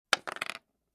StoneSound5.mp3